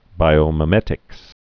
(bīō-mĭ-mĕtĭks, -mī-)